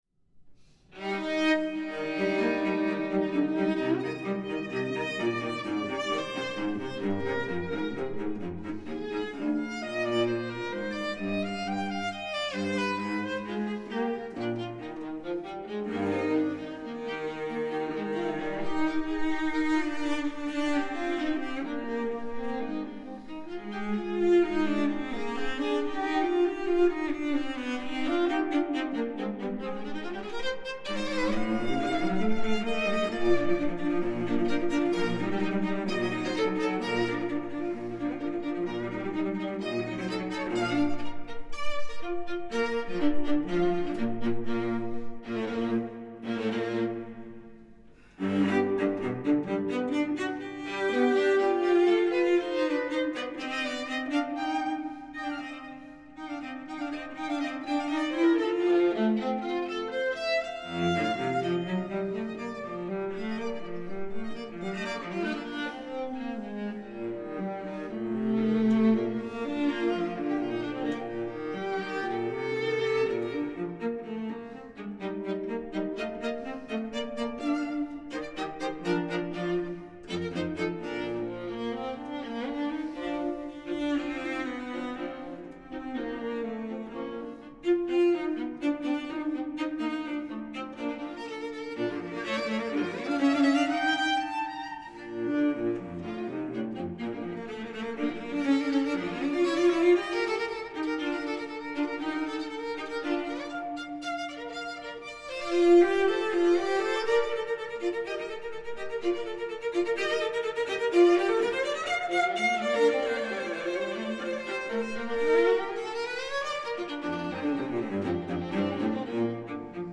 Beethoven duet for viola and cello